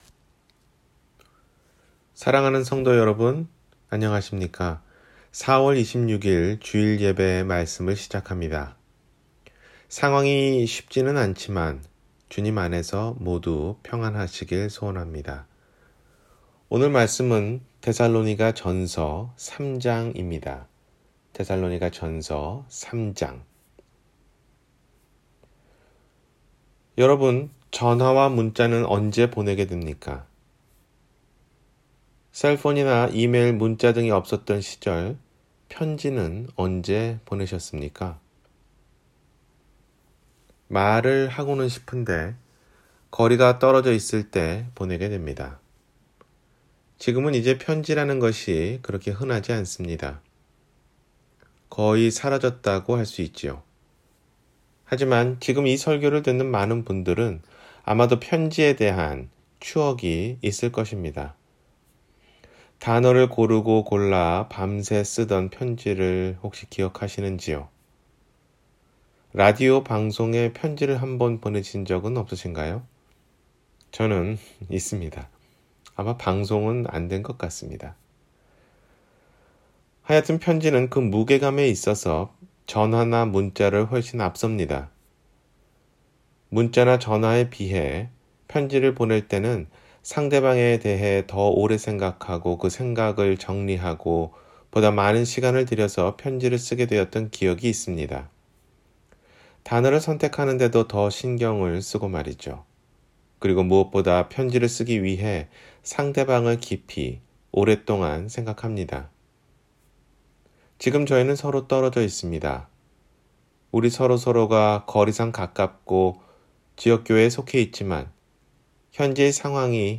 서로를 위함이란 (위로에 대해) – 주일설교